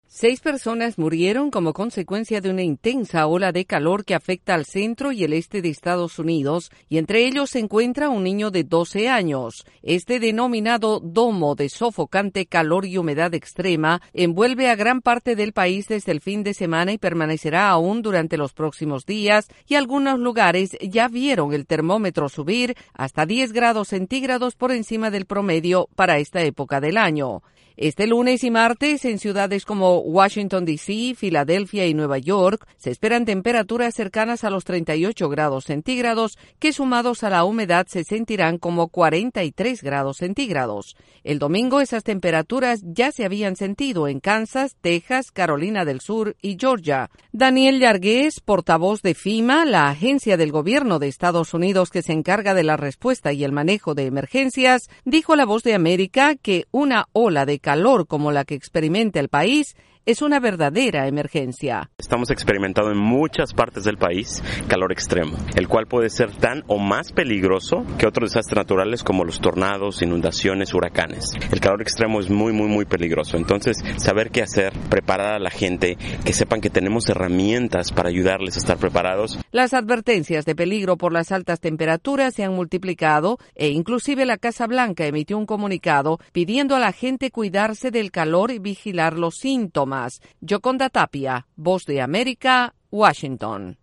Autoridades en Estados Unidos advierten sobre el peligro de un peligrosa ola de calor que afecta a una gran parte del centro y el este del país. Desde la Voz de América en Washington DC informa